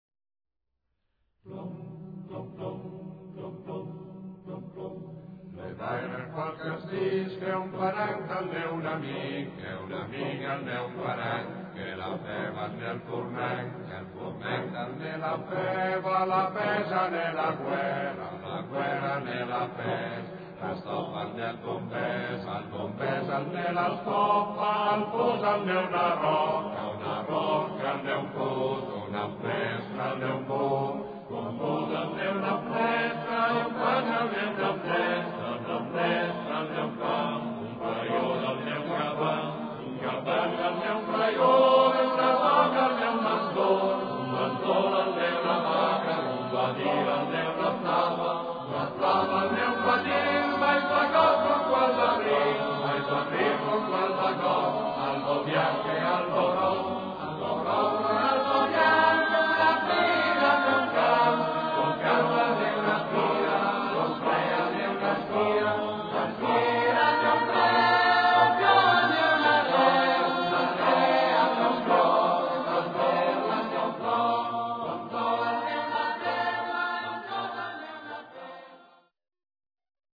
Ricerca, elaborazione, esecuzione di canti popolari emiliani
voci miste